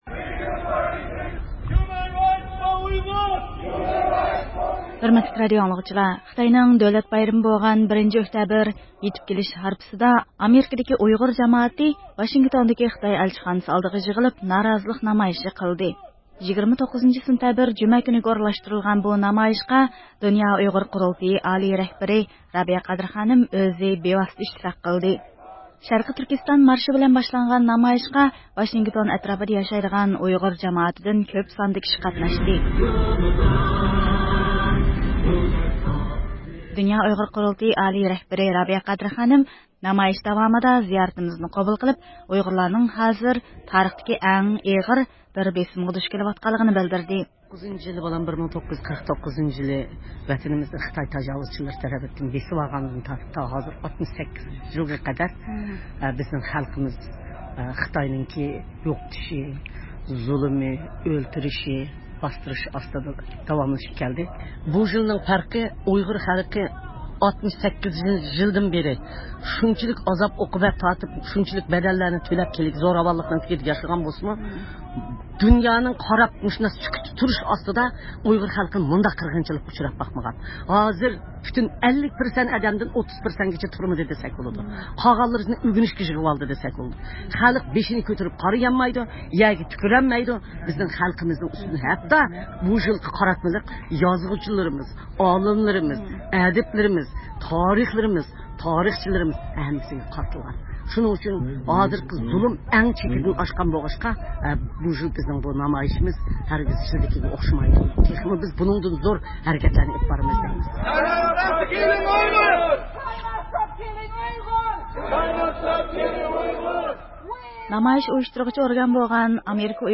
شەرقىي تۈركىستان مارشى بىلەن باشلانغان نامايىشقا ۋاشىنگتون ئەتراپىدا ياشايدىغان ئۇيغۇر جامائىتىدىن كۆپ ساندا كىشى قاتناشتى.
دۇنيا ئۇيغۇر قۇرۇلتىيى ئالىي رەھبىرى رابىيە قادىر خانىم رادىيومىز زىيارىتىنى قوبۇل قىلىپ، ئۇيغۇرلارنىڭ ھازىر تارىختىكى ئەڭ ئېغىر بېسىمغا دۇچ كېلىۋاتقانلىقىنى بىلدۈردى.
ئاي يۇلتۇزلۇق كۆك بايراقلار بىلەن تولغان خىتاي ئەلچىخانىسى ئالدىدىكى نامايىش قىزغىن كەيپىيات ئىچىدە داۋاملاشتى.